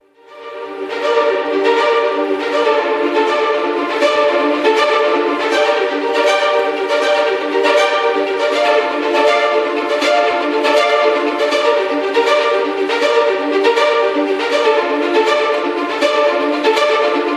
细致入微的弦乐器库
精细和清晰的声音
四个麦克风混音，带有混音台界面